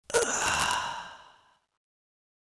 Last Breath.wav